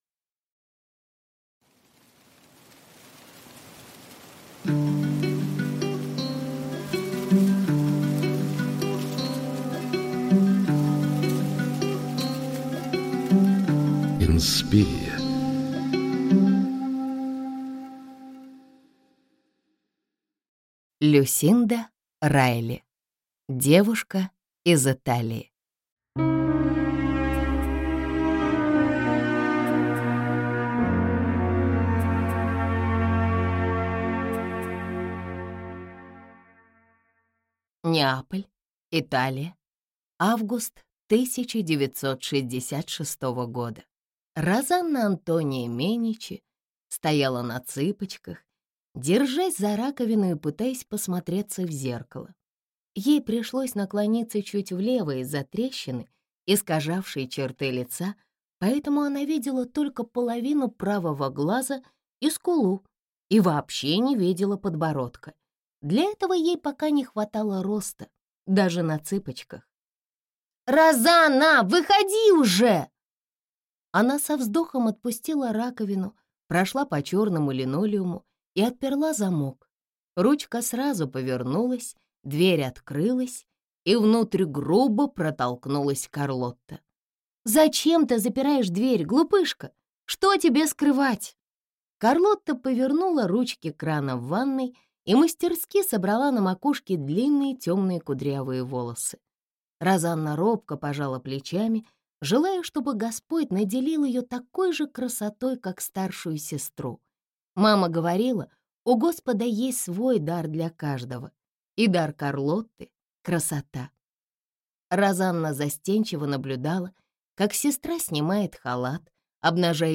Девушка из Италии (слушать аудиокнигу бесплатно) - автор Люсинда Райли